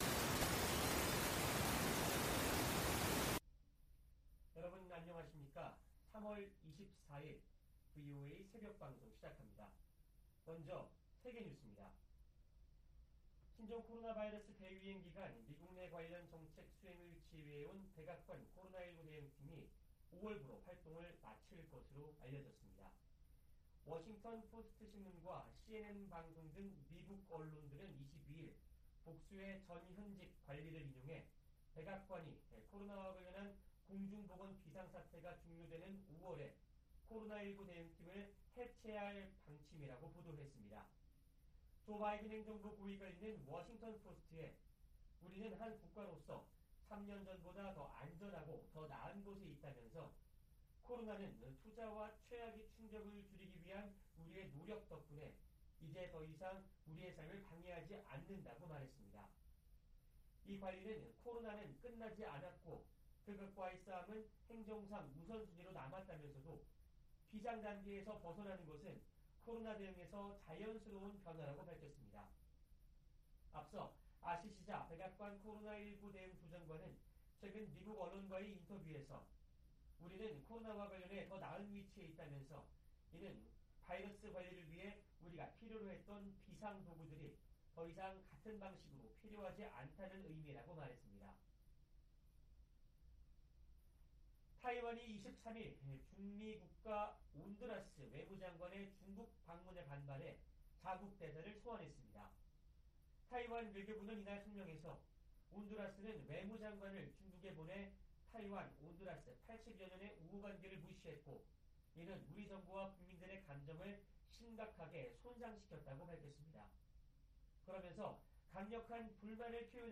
VOA 한국어 '출발 뉴스 쇼', 2023년 3월 24일 방송입니다. 백악관은 북한의 핵 공격이 임박했다는 징후는 없지만 최대한 면밀히 주시하고 있다고 밝혔습니다. 북한은 국제사회의 비핵화 요구를 핵 포기 강요라며 선전포고로 간주하고 핵으로 맞서겠다고 위협했습니다. 미국 국무부가 한국 정부의 최근 독자 대북제재 조치에 환영의 입장을 밝혔습니다.